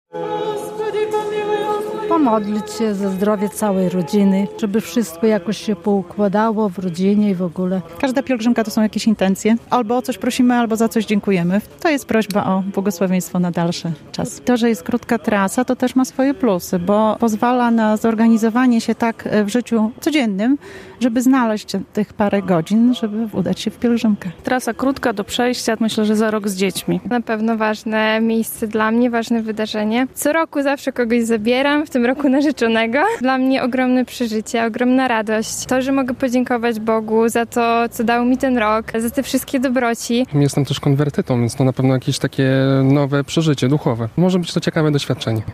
Jestem konwertytą, więc dla mnie to nowe duchowe przeżycie i nowe doświadczenie - mówią pątnicy